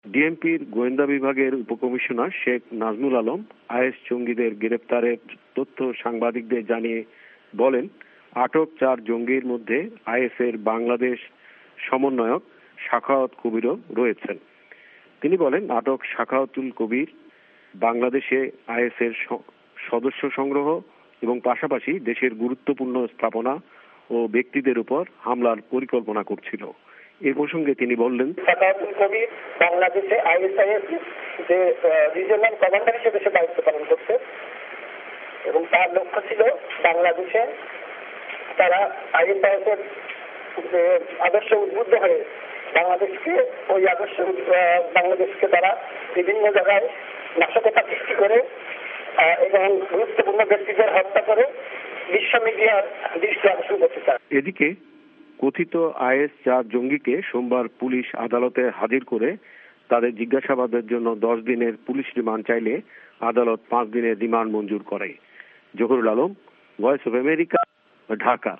ভয়েস অফ এ্যামেরিকার বাংলাদেশ সংবাদদাতাদের রিপোর্ট